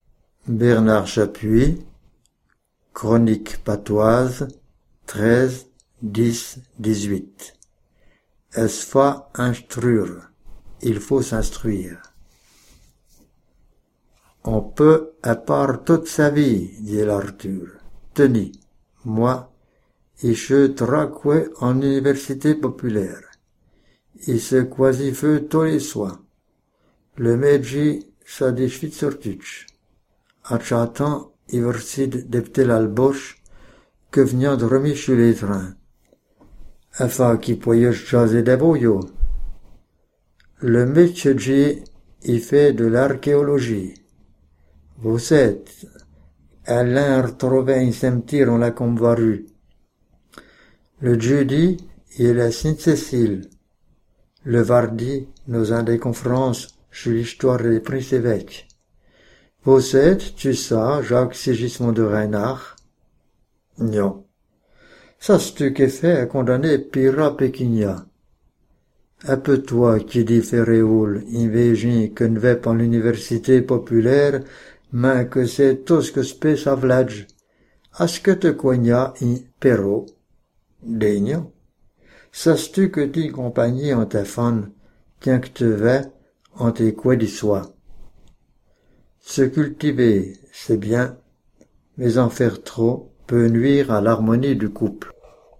– Ç’ât çtu que tïnt compaingnie en tai fanne tiaind qu’te vais en tes coés di soi.} Se cultiver, c’est bien, mais en faire trop peut nuire à l’harmonie du couple. ---- Ecouter la chronique lue